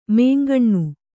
♪ mēngaṇṇu